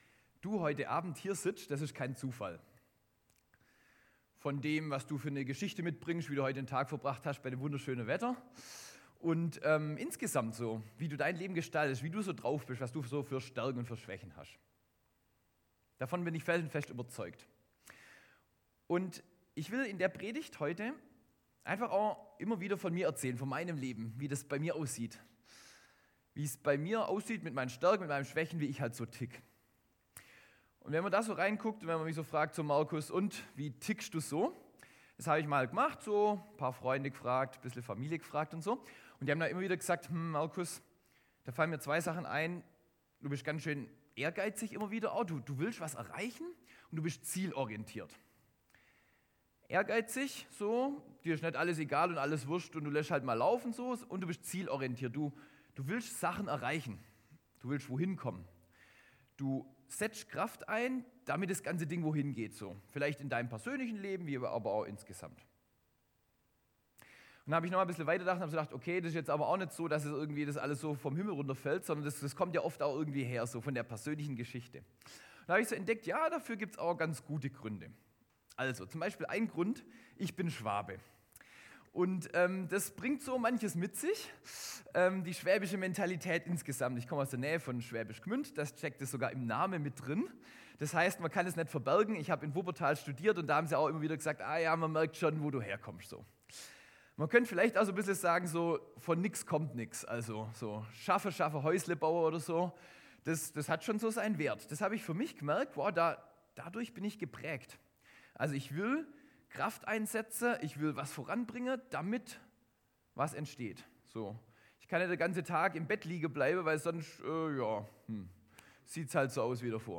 Go In Gottesdienst am 12.05.2024